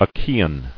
[A·chae·an]